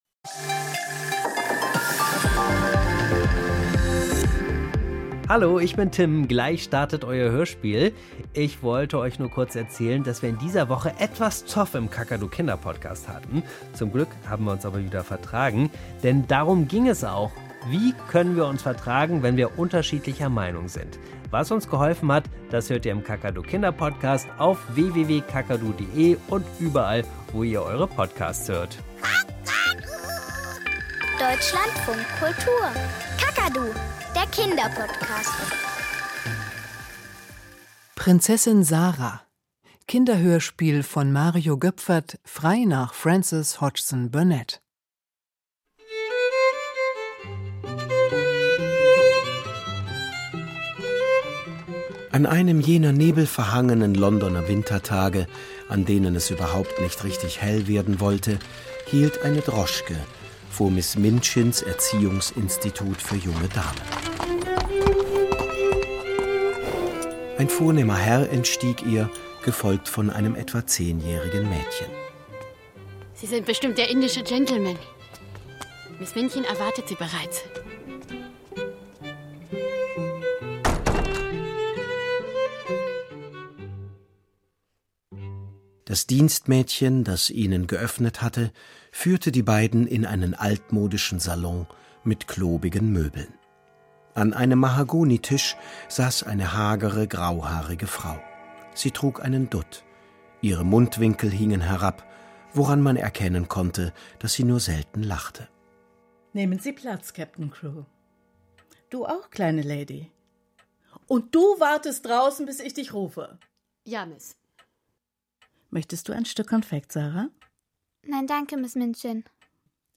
Im Kinderhörspiel von Deutschlandfunk Kultur werden Geschichten lebendig. In unseren Hörspielstudios zaubern wir die unterschiedlichsten Welten herbei: einen Elfenwald, die Tiefsee oder eine Mäusehöhle. Und all das nur durch Musik, Geräusche und die Spielfreude der Schauspieler und der vielen begabten Kinder.